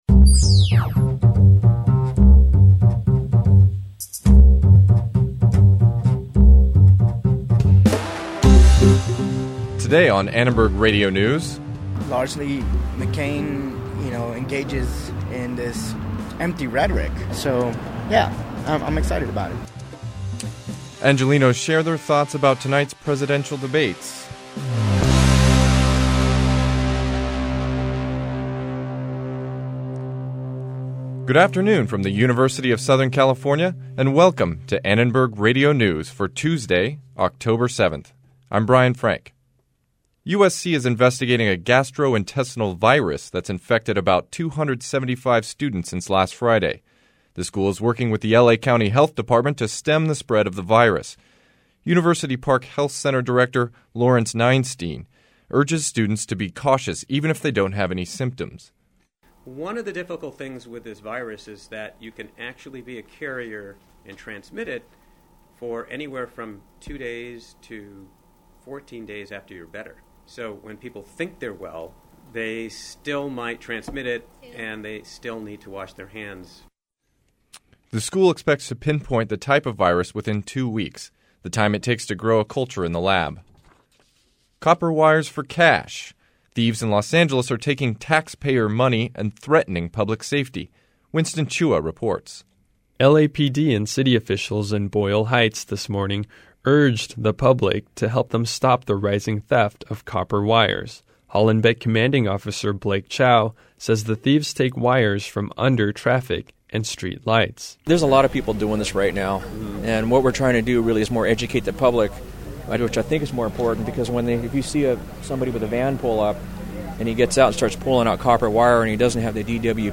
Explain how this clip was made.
ARN Live Show - October 7, 2008 | USC Annenberg Radio News